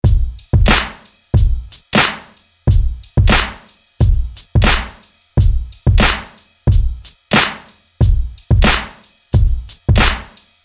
DRUMMAH loop 90 Bpm.wav